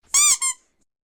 squeak2.ogg